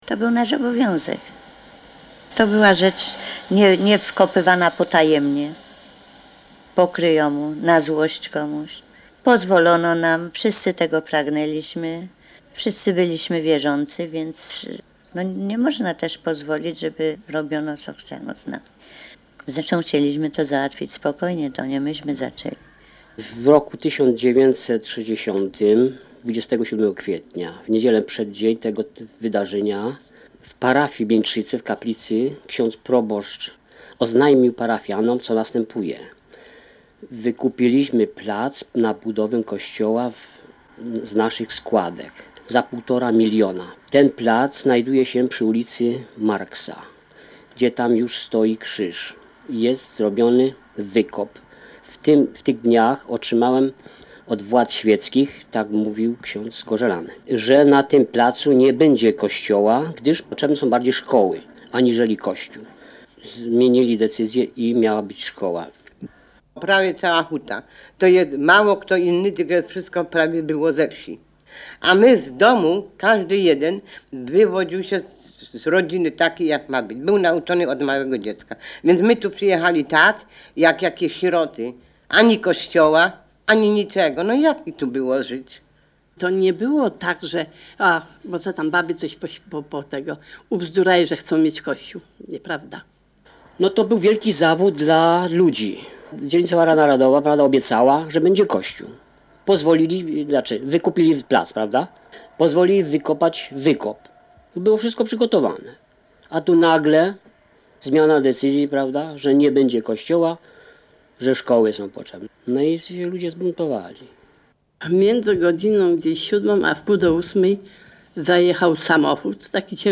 Reportaż w Radiu Kraków